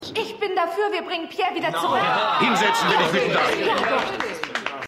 AusgerechnetAlaska_3x06_Frau.mp3